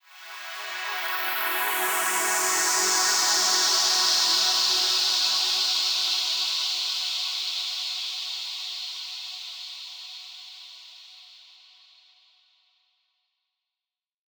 SaS_HiFilterPad01-E.wav